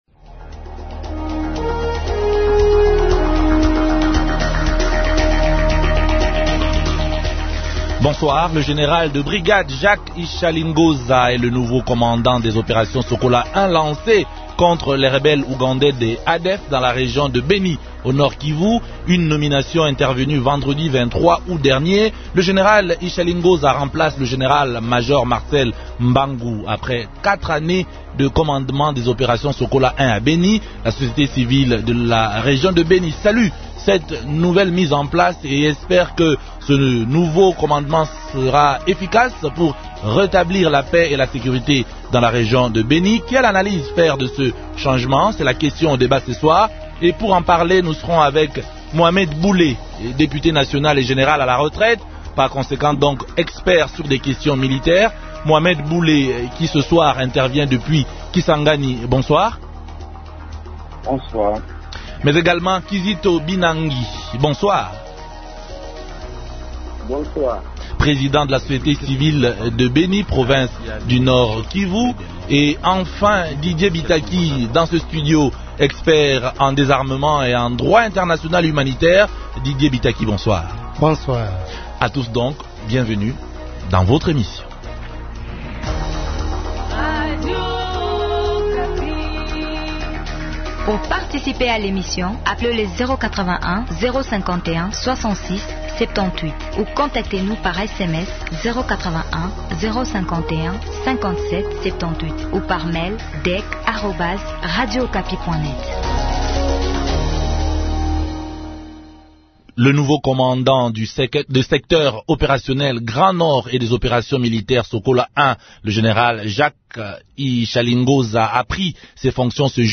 Il est expert sur les questions militaires.